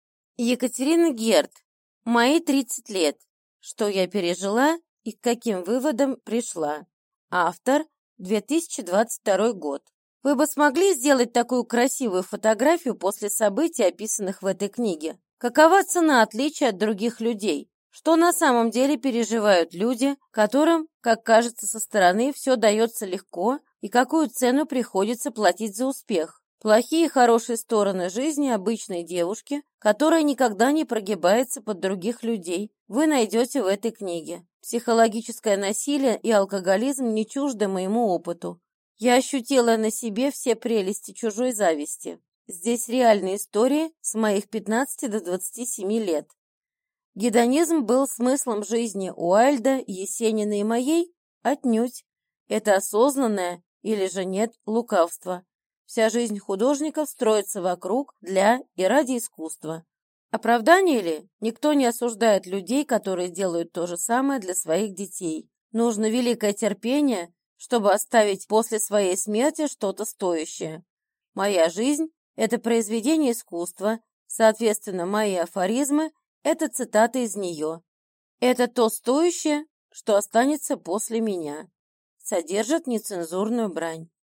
Аудиокнига Мои 30 лет. Что я пережила и к каким выводам пришла | Библиотека аудиокниг